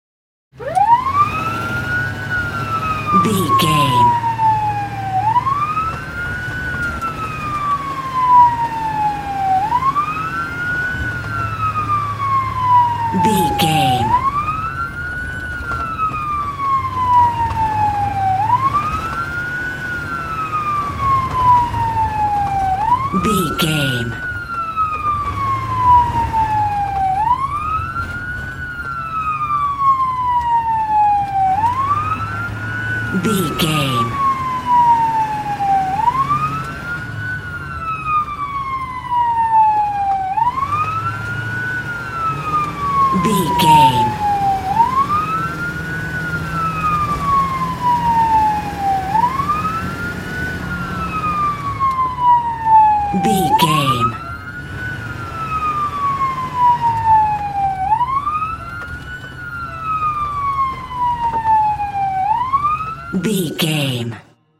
Ambulance Int Drive Engine Large Siren
Sound Effects
urban
chaotic
emergency